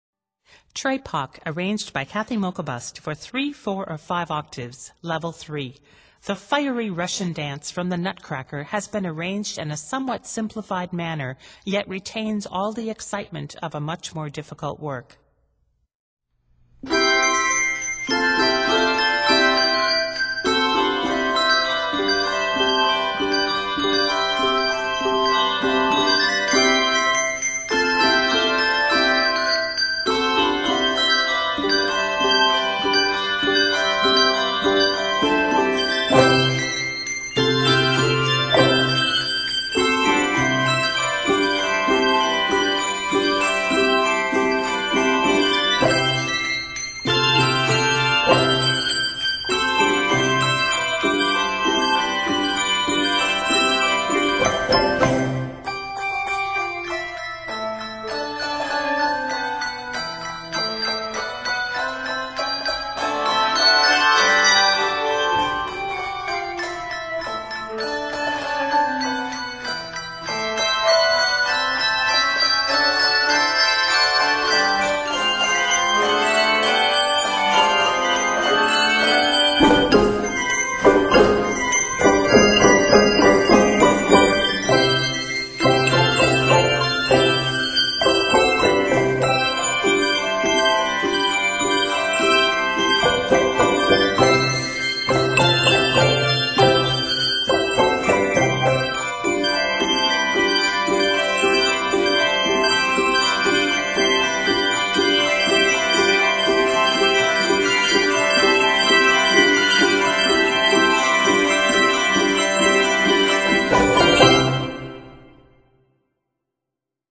is arranged in C Major and is 84 measures.